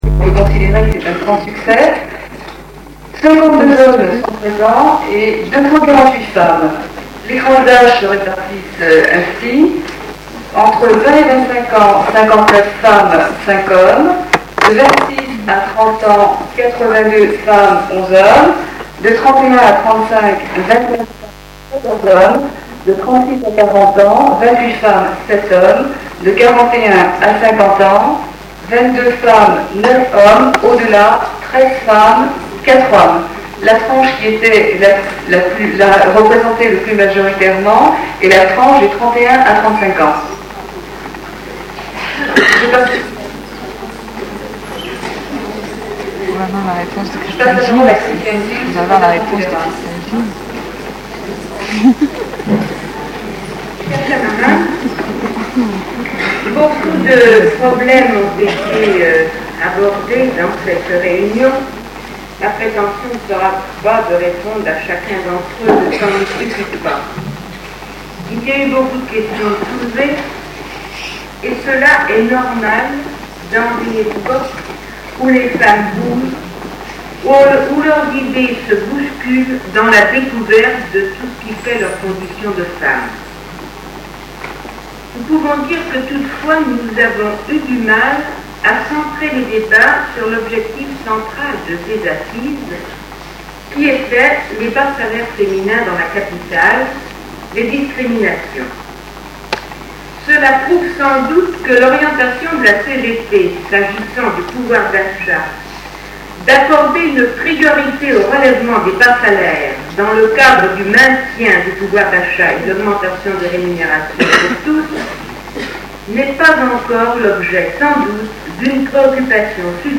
Violences faites aux femmes, discriminations, inégalités de salaires, métiers d’appoint, double journée de travail...Je vous propose un regard rétrospectif au sujet des femmes travailleuses avec quelques archives sonores inédites. En effet, je crois bien avoir été la seule, en 1977, à avoir enregistré les journées des travaux de la 6ème Conférence féminine de la C.G.T.(archives sonores en bas de page) .
Je n’ai pas pu sauver tout ce que j’avais enregistré et deux cassettes, parmi celles que j’ai retrouvées, ont été, en partie, démagnétisées.